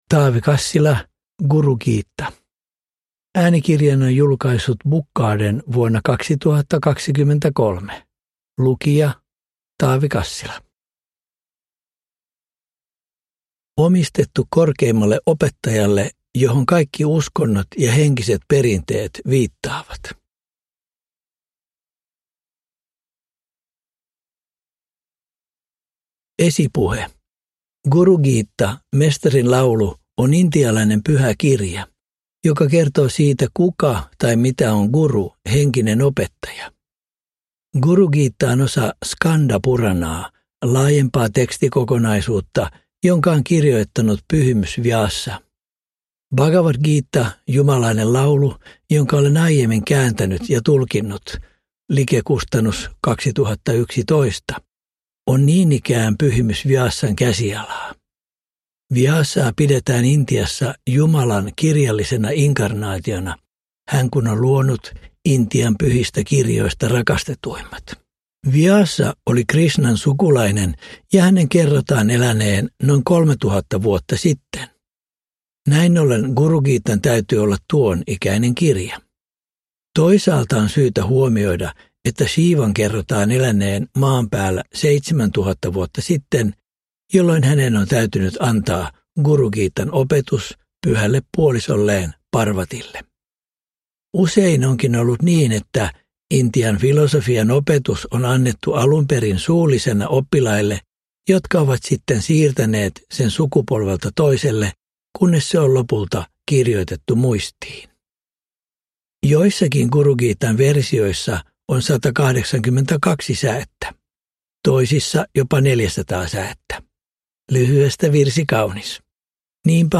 Guru-Gita – Ljudbok – Laddas ner